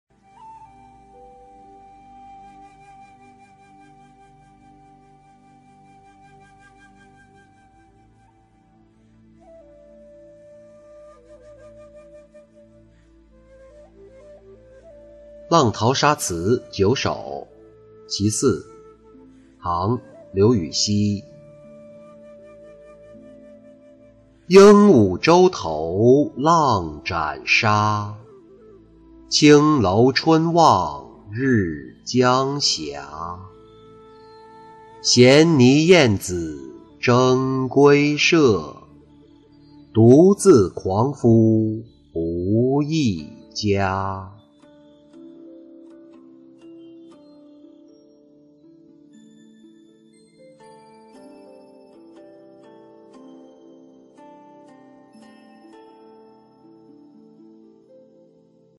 浪淘沙·其四-音频朗读